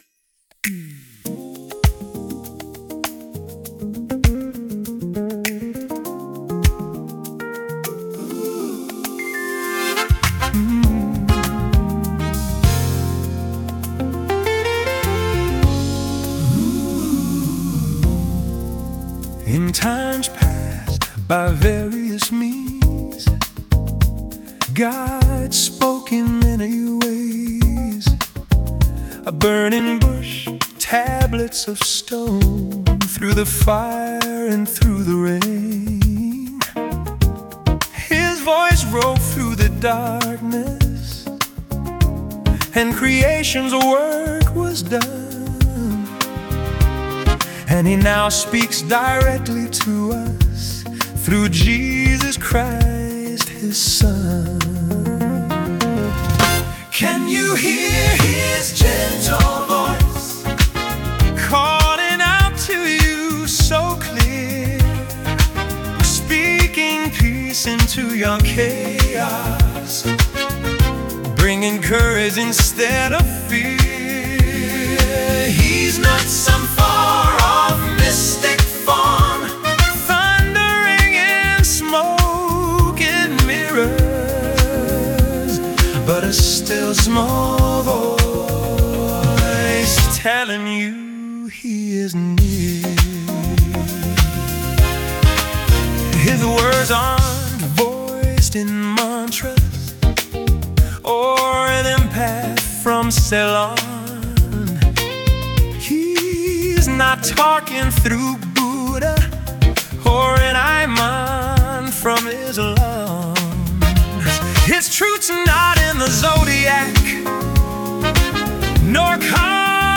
Love it so up beat and a great message.
Great vocals love the brass.
Great upbeat tempo.